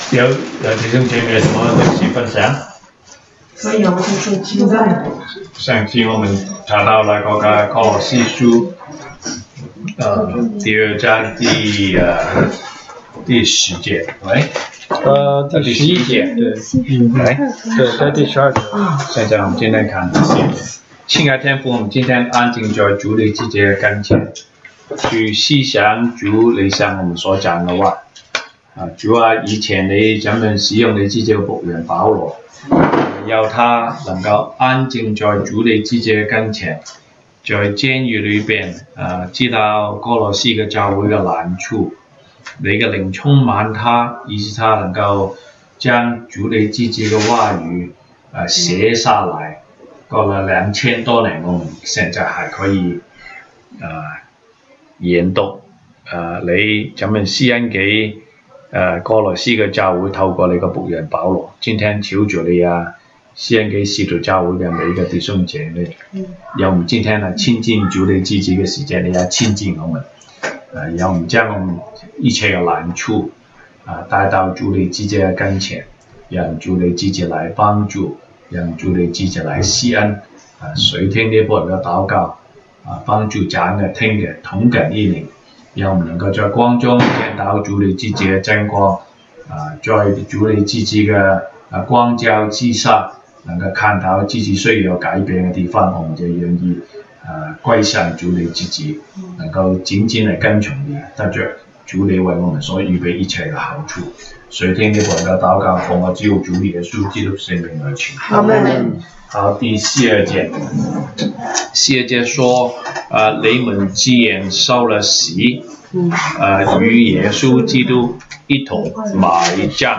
Service Type: 週一國語研經 Monday Bible Study